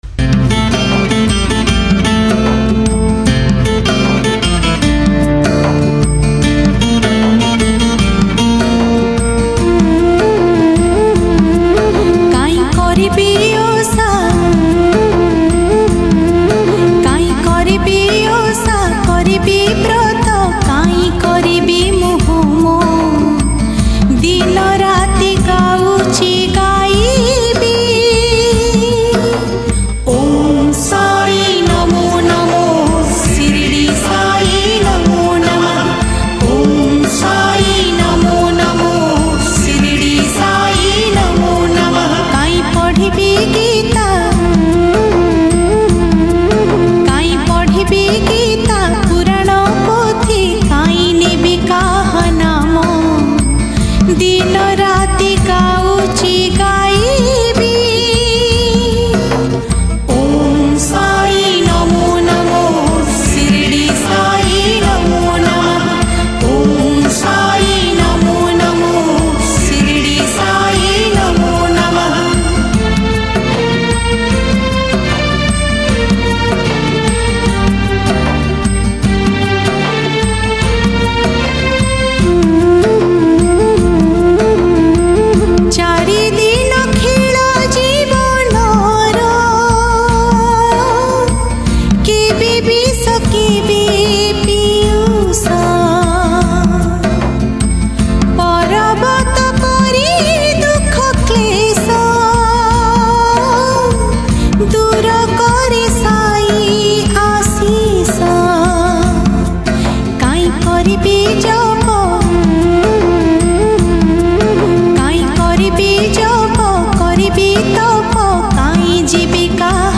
Odia Sai Bhajan
Category: Odia Bhakti Hits Songs